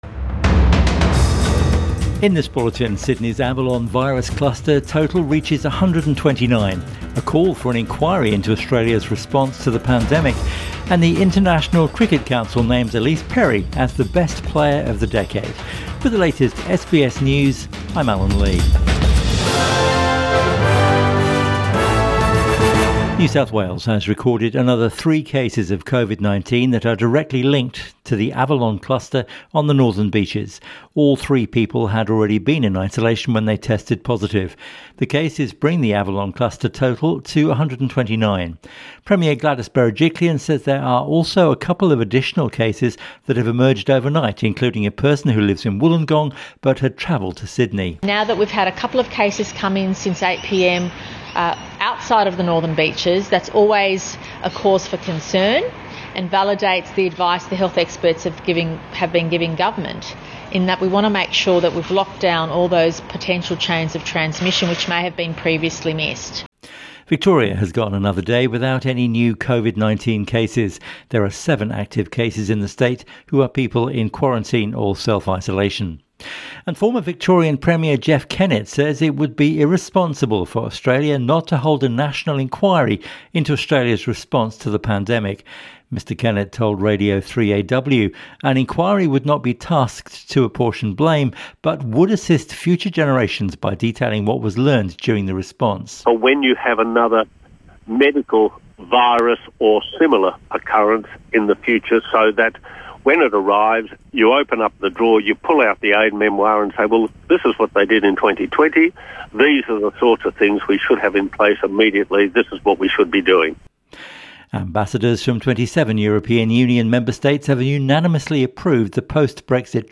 Midday Bulletin 29 December 2020